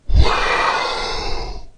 标签： 语音 娱乐 女性 死说了 伤了 注意 杀人 演员 心烦 自杀 样品
声道立体声